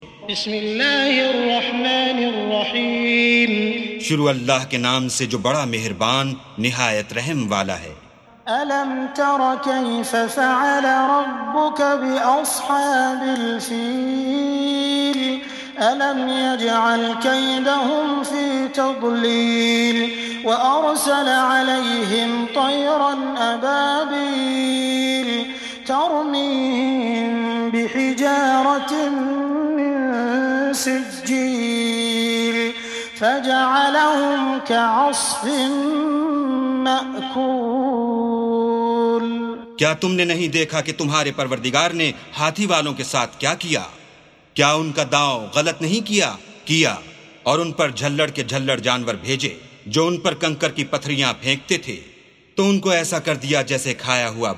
سُورَةُ الفِيلِ بصوت الشيخ السديس والشريم مترجم إلى الاردو